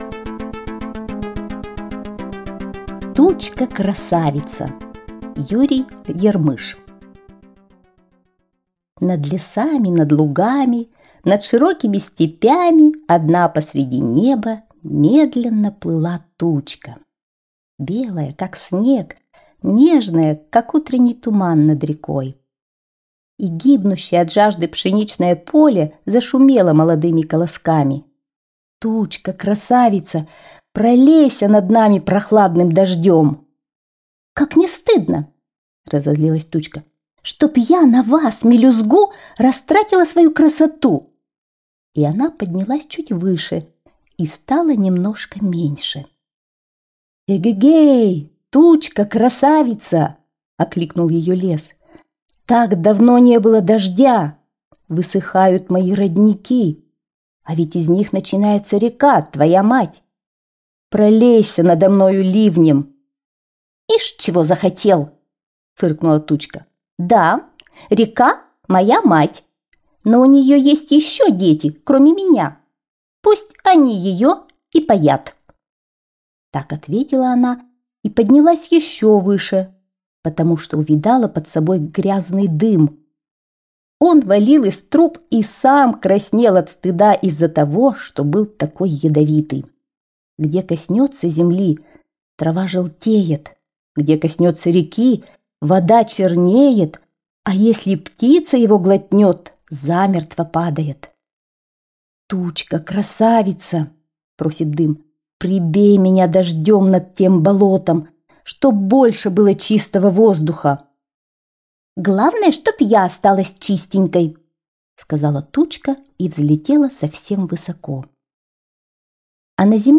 Тучка-красавица - аудиосказка Ярмыша Ю. Тучка плыла по небу и любовалась собой.